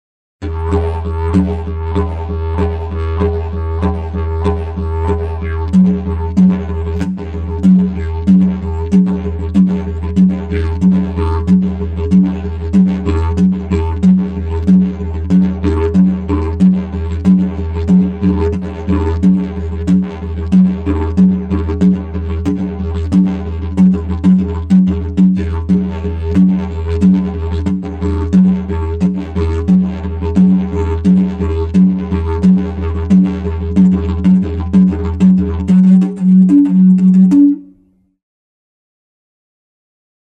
9.7 Effetto tromba o TOOT
Sample n°28 contiene: applicazione della respirazione circolare con inspirazione sul toot (è necessario aver appreso almeno una tecnica per comporre ritmi, vedi prossimo capitolo).